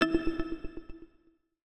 UIClick_Long Modern Echo 01.wav